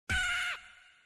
Chicken Gun Death Bouton sonore